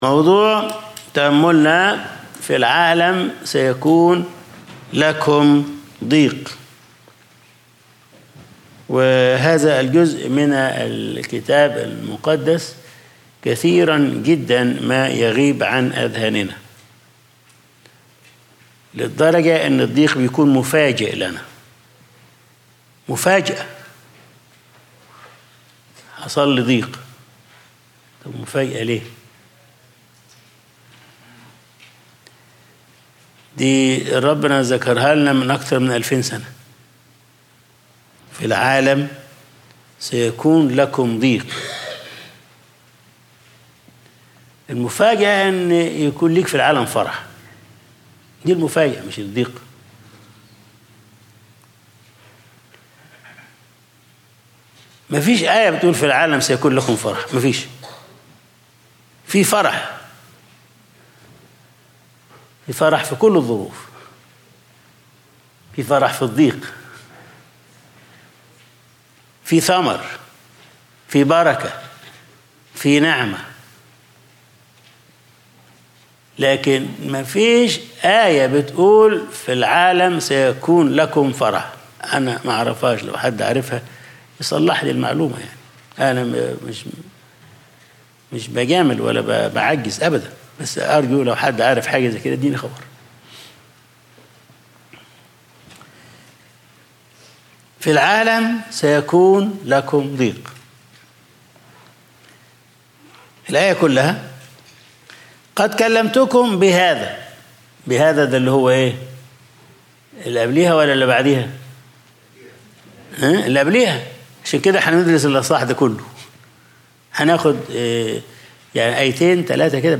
Sunday Service | في العالم سيكون لكم ضيق